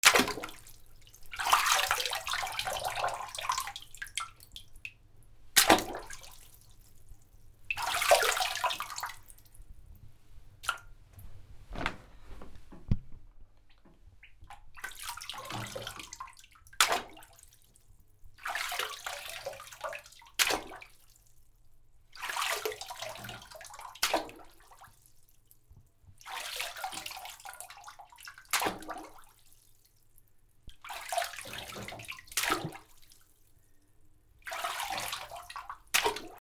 水に落とす 水から出したり入れたり
『チャポン』